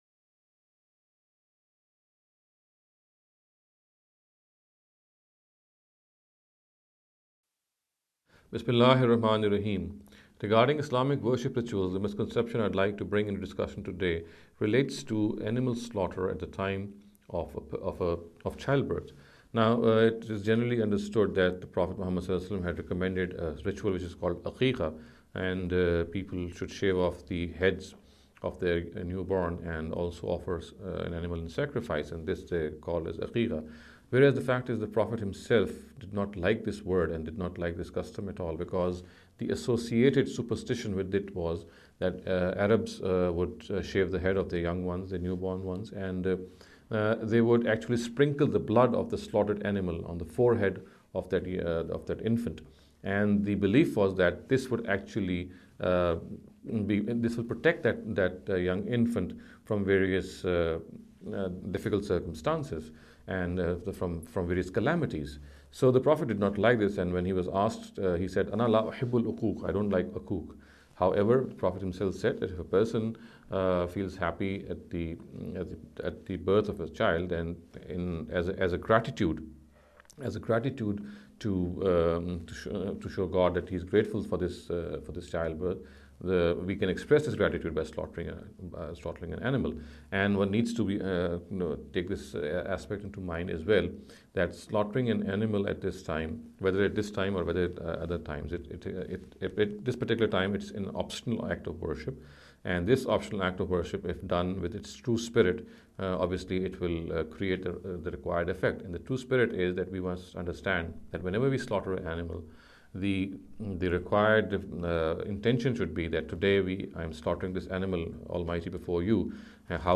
This lecture series will deal with some misconception regarding the Islamic Worship Rituals. In every lecture he will be dealing with a question in a short and very concise manner. This sitting is an attempt to deal with the question 'Animal Sacrifice at Childbirth’.